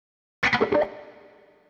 guitar02.wav